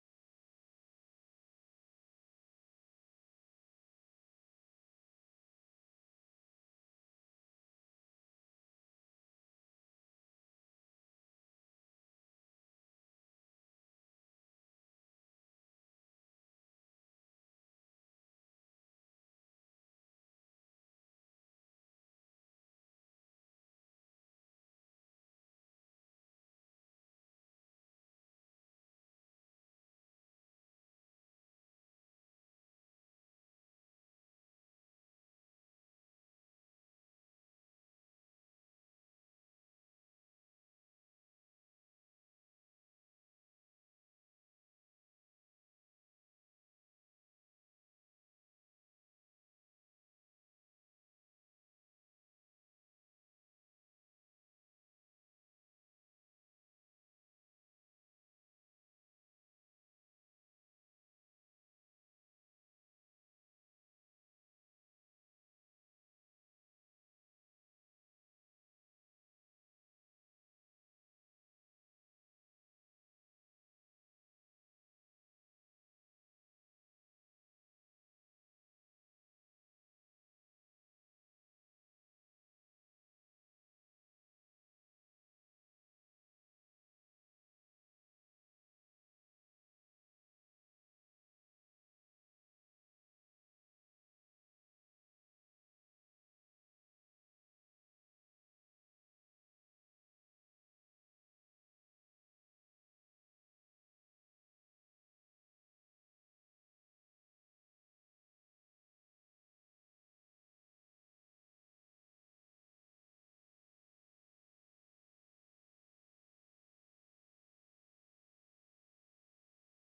Mbarara, Uganda (seismic) archived on November 2, 2018
Station : MBAR (network: IRIS/IDA) at Mbarara, Uganda
Sensor : Geotech KS54000 triaxial broadband borehole seismometer
Speedup : ×1,800 (transposed up about 11 octaves)
Loop duration (audio) : 05:36 (stereo)